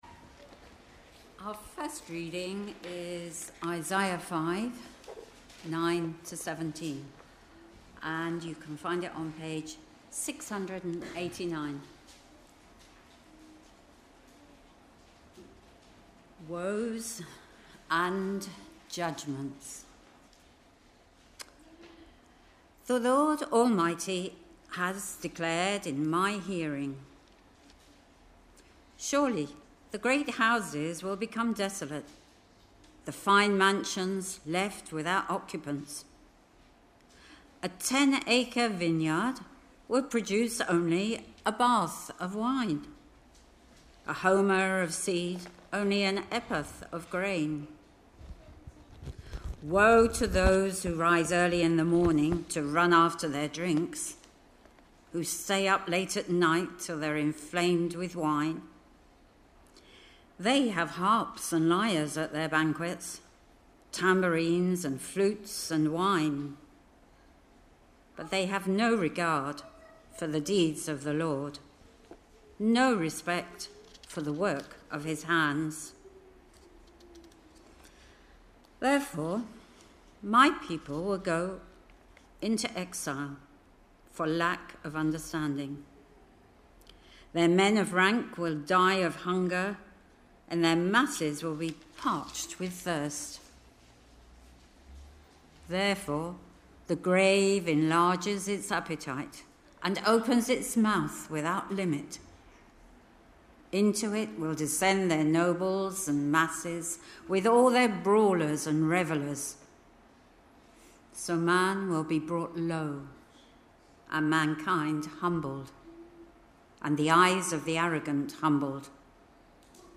Readings-Sermon-on-1st-March-2026.mp3